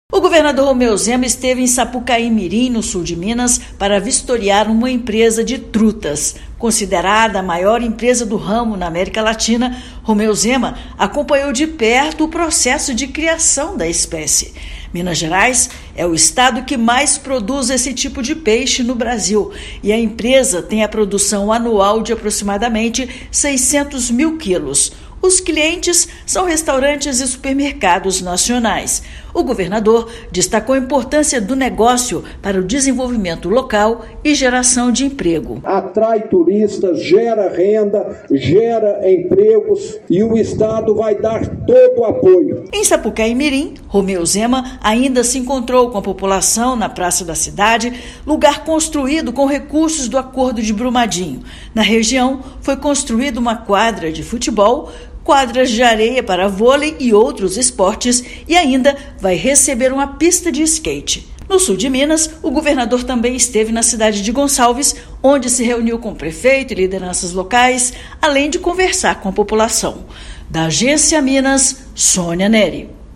Minas Gerais lidera ranking nacional de produção do peixe; em agenda encerrada nesta sexta-feira (15/6), também houve entregas do governo nas áreas de saúde, esportes e educação. Ouça matéria de rádio.